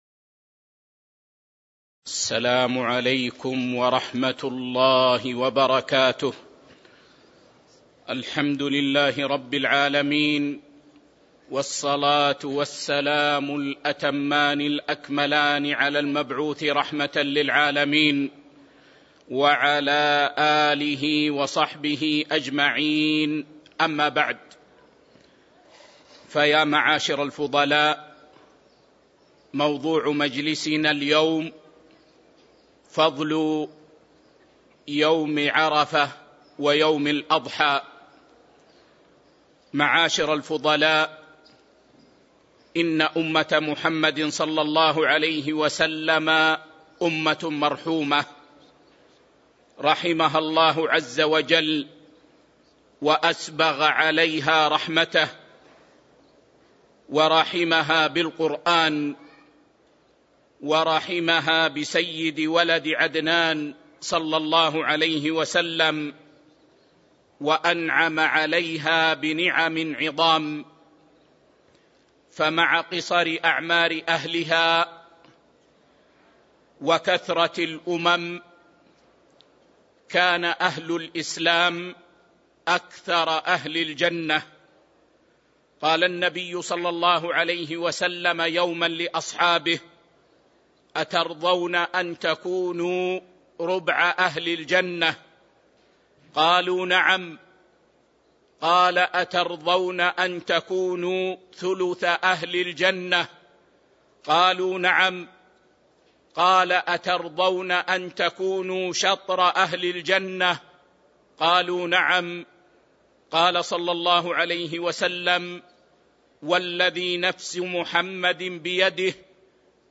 تاريخ النشر ٨ ذو الحجة ١٤٤٣ المكان: المسجد النبوي الشيخ